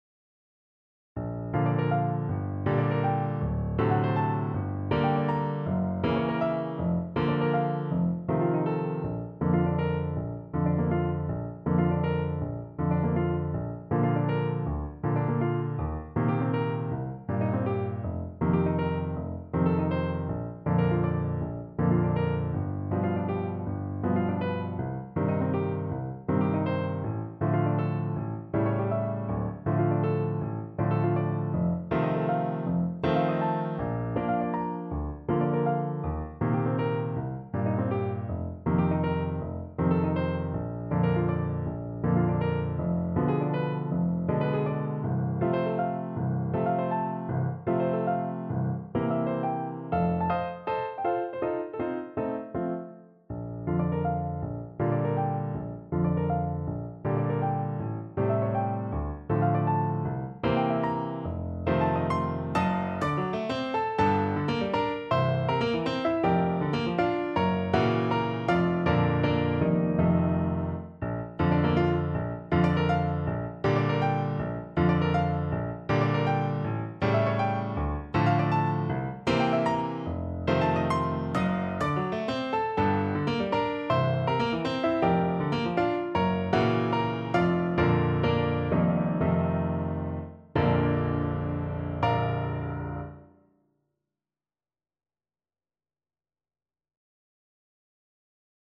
6/8 (View more 6/8 Music)
Classical (View more Classical Saxophone Music)